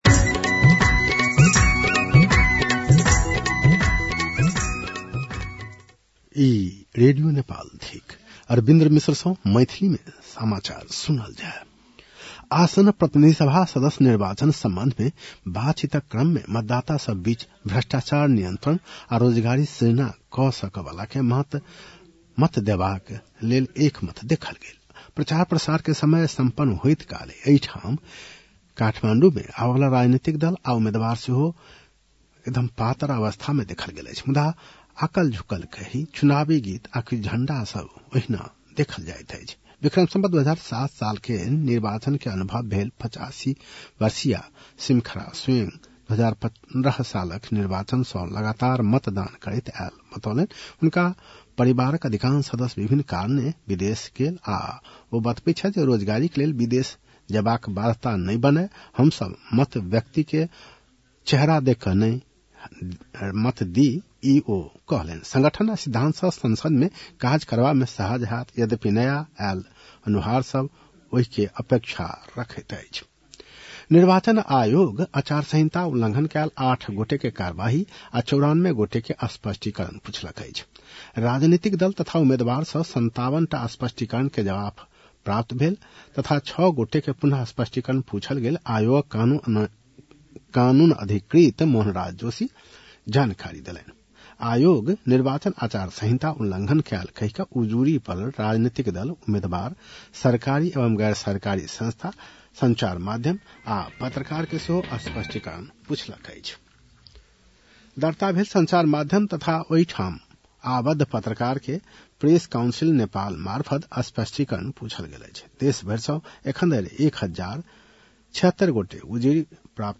मैथिली भाषामा समाचार : १६ फागुन , २०८२